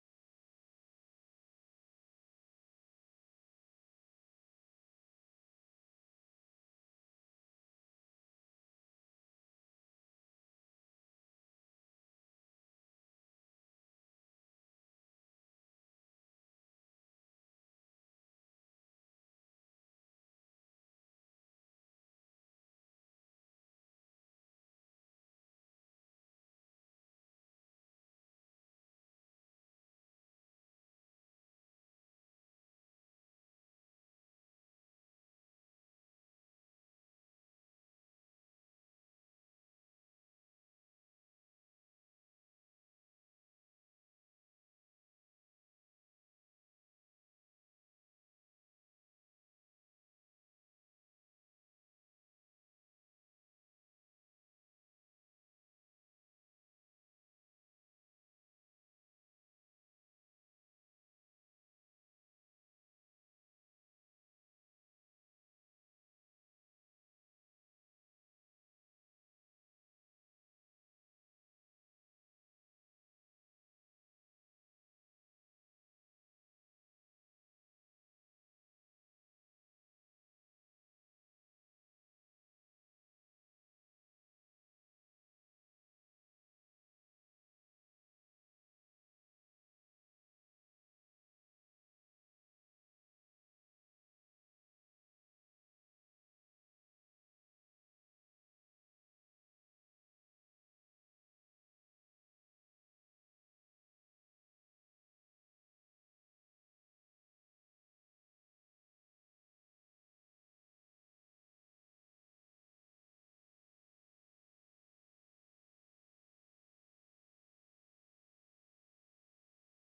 Прямой-эфир.mp3